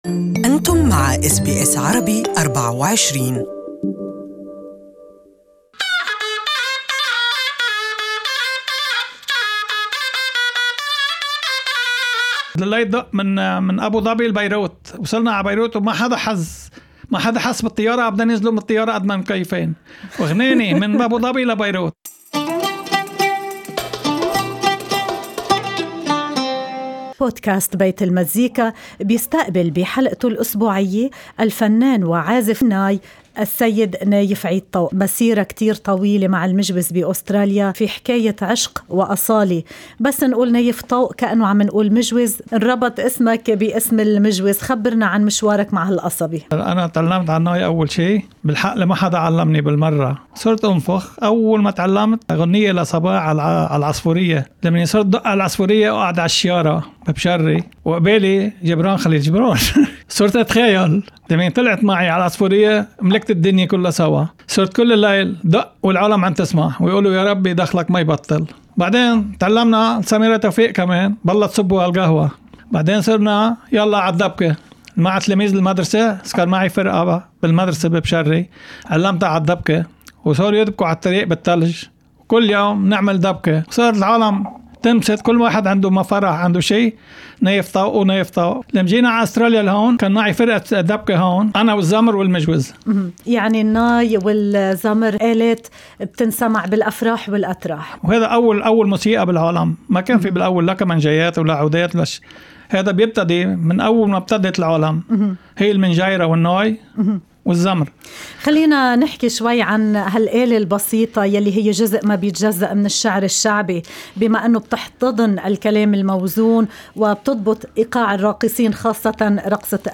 وتعتبر المجوز آلة موسيقية بسيطة مميزة الصوت، يمكنكم الاستماع لها في الملف الصوتي المرفق.